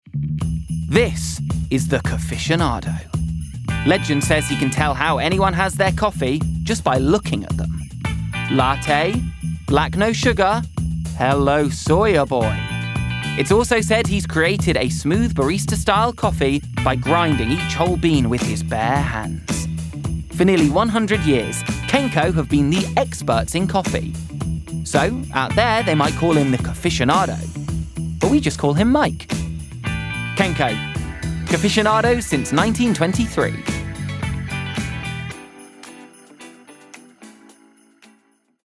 Kenco - Bright, Perky, Fun